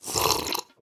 drink_milk4.ogg